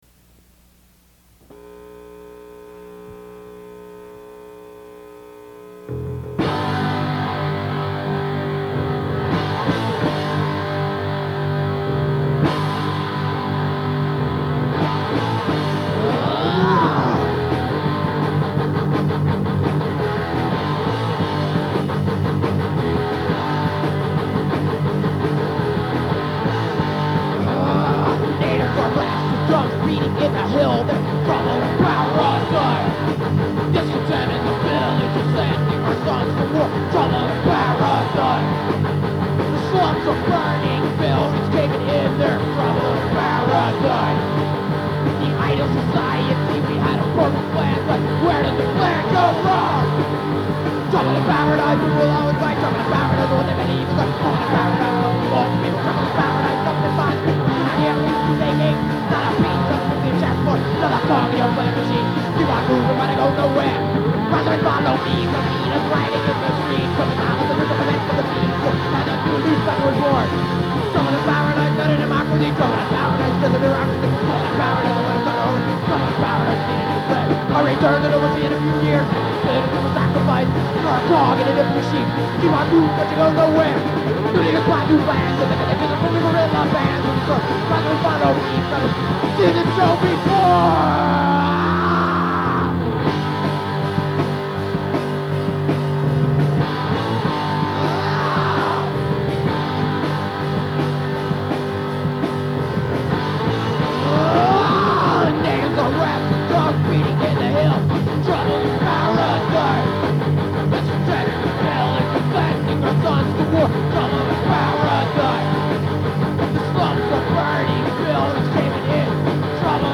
demo tape
Guitar
Drums
on bass.